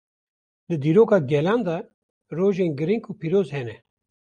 Pronounced as (IPA)
/ɡɪˈɾiːŋɡ/